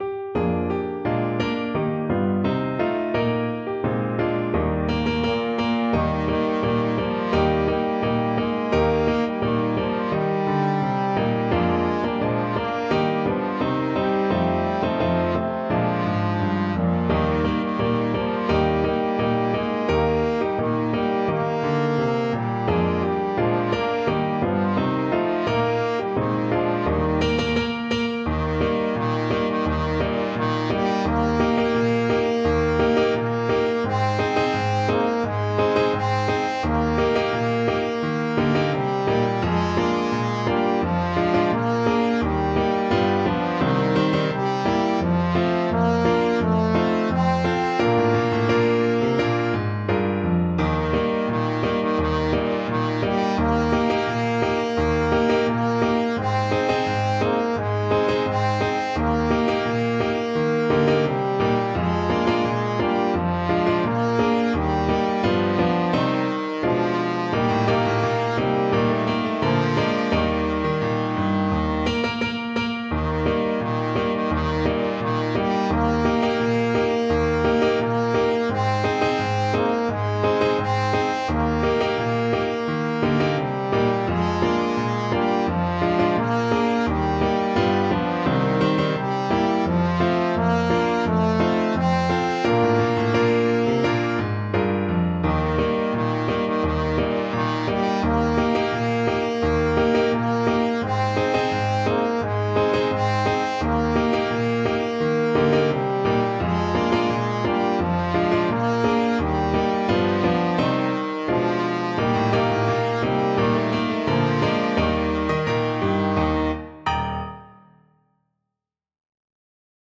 Trombone
Eb major (Sounding Pitch) (View more Eb major Music for Trombone )
Tempo di Marcia = c.86
2/2 (View more 2/2 Music)
Traditional (View more Traditional Trombone Music)